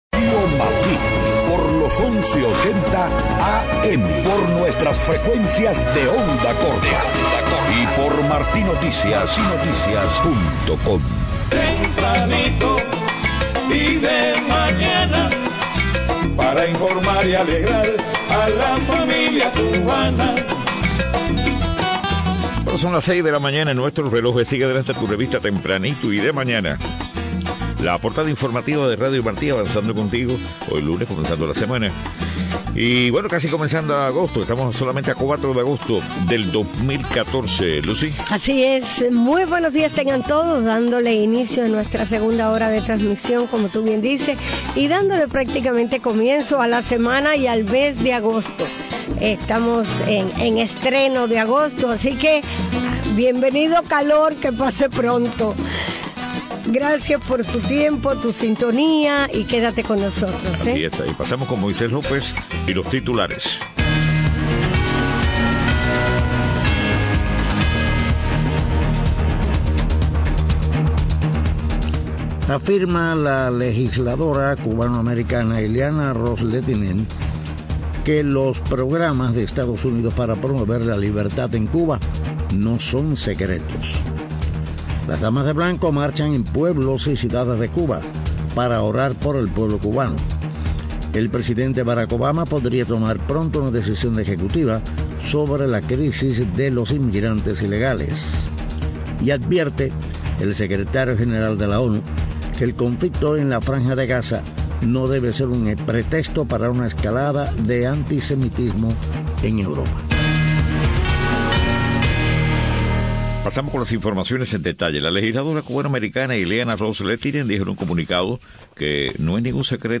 6:00 a.m. Noticias: Legisladora Ileana Ros-Lethinen afirma que programas de EEUU para promover libertad en Cuba no son secretos. Damas de blanco marchan en pueblos y ciudades de Cuba para orar por el pueblo. Presidente Obama podría tomar pronto decisión ejecutiva sobre crisis de inmigrantes ilegales.